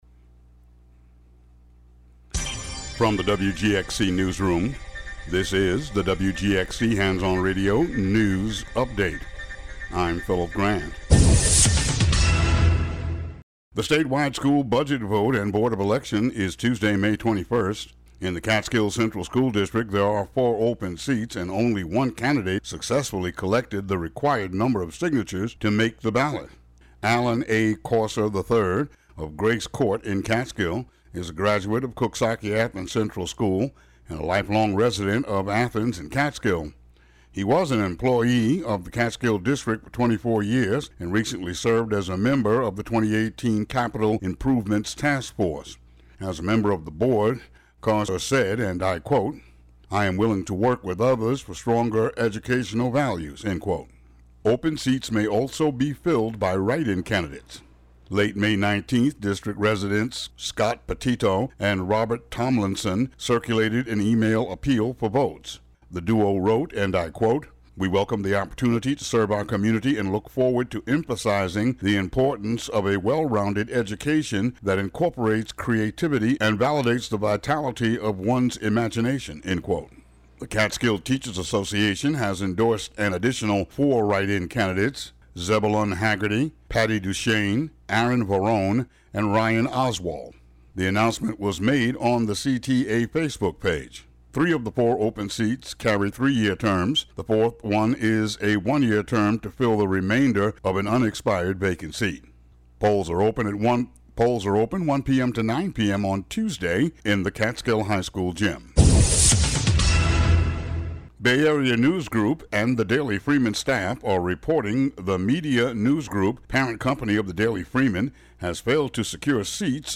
Today's daily news.